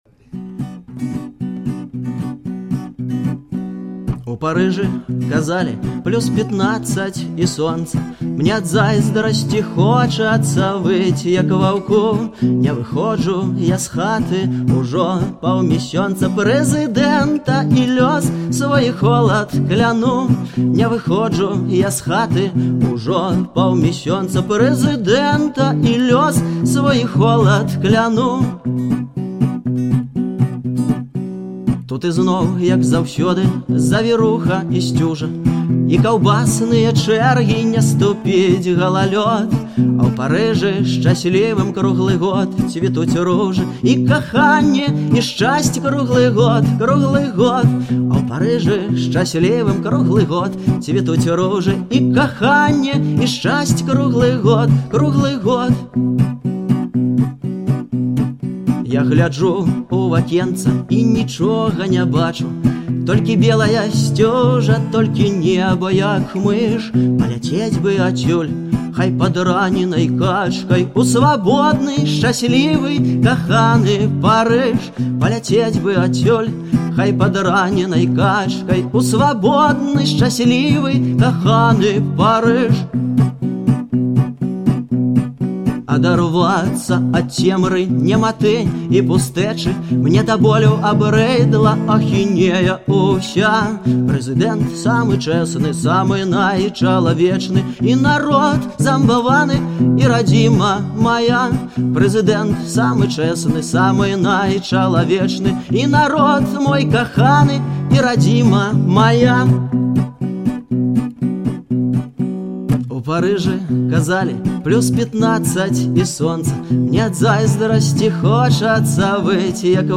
архіўны запіс